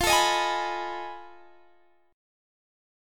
F7#9b5 Chord
Listen to F7#9b5 strummed